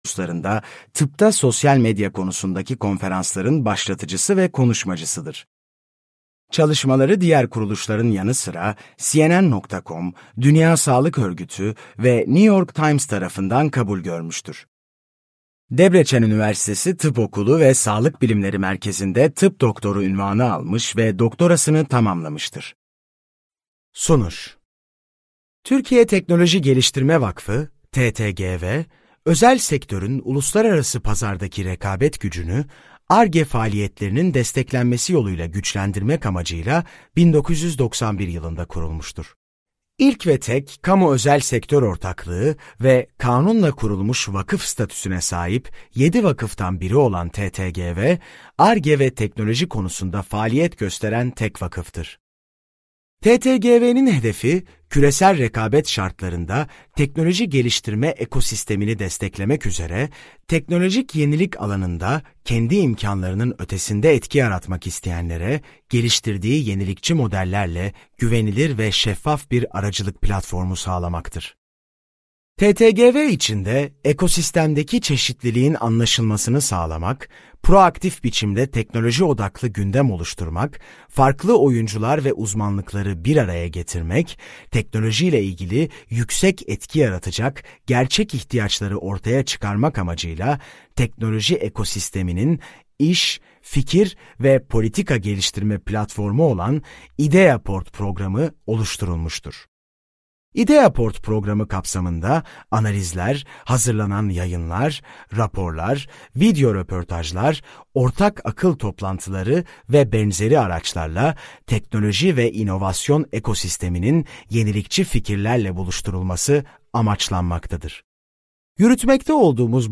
Tıbbın Geleceğine Yolculuk - Seslenen Kitap
Seslendiren